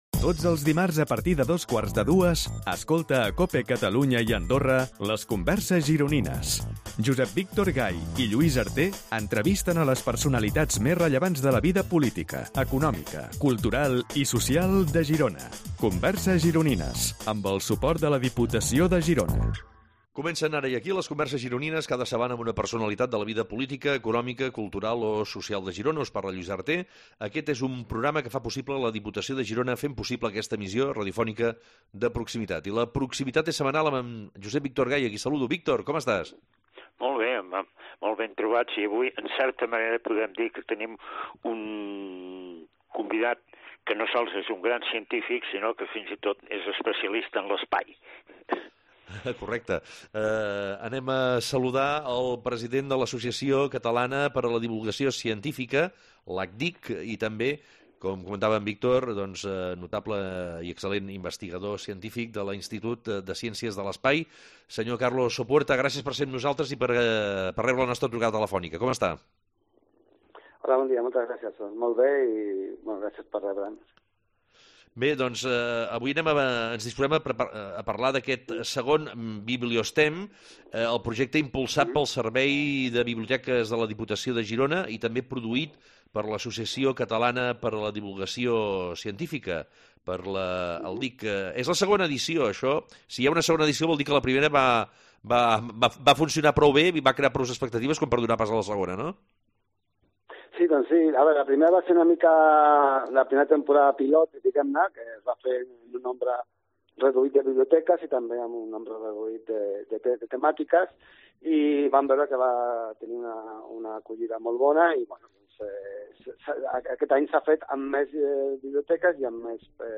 A “Converses Gironines” entrevistem algunes de les grans personalitats rellevants de la vida política, econòmica cultural o social de Girona.
Aquestes converses es creen en un format de tertúlia en el que en un clima distès i relaxat els convidats ens sorprenen pels seus coneixements i pel relat de les seves trajectòries. Actualment el programa s’enregistra i emet en els estudis de la Cadena Cope a Girona, situats en el carrer de la Sèquia número tres de Girona, just al costat del museu del Cinema.